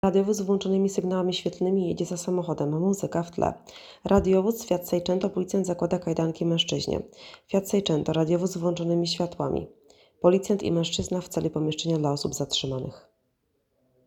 Nagranie audio audiodeskrycja filmu.m4a